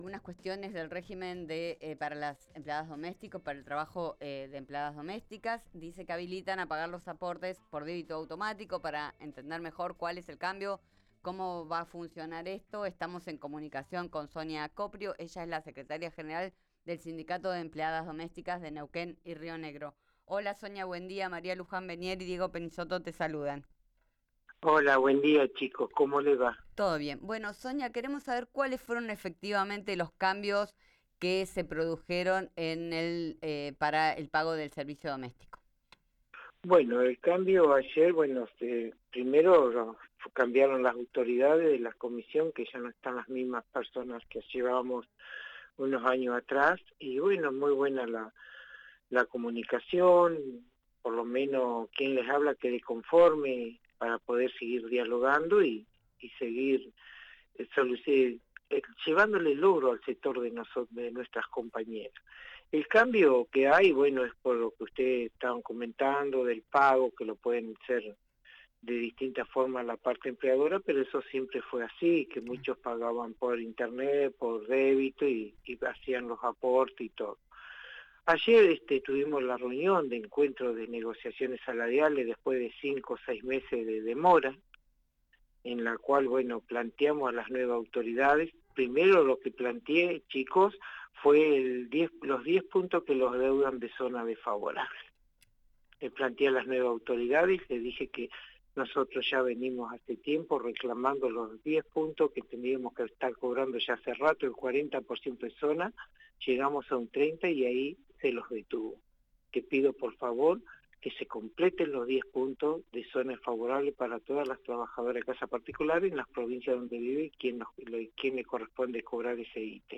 En diálogo con «Vos al Aire«